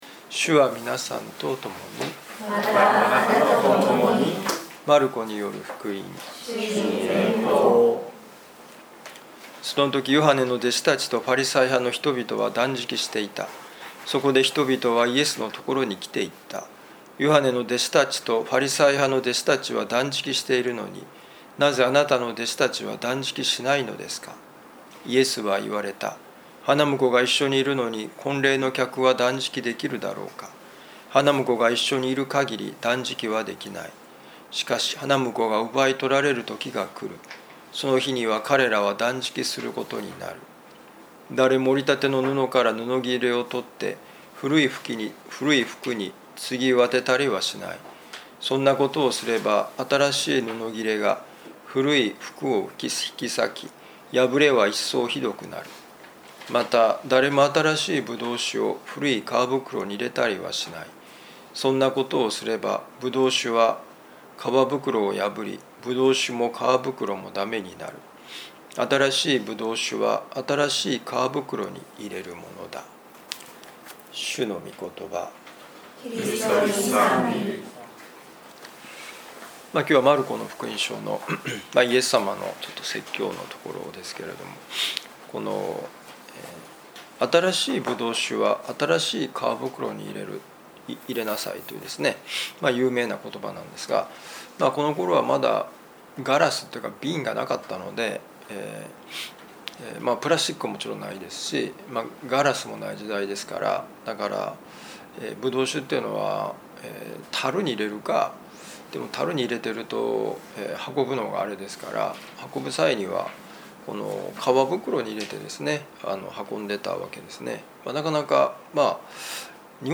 【ミサ説教】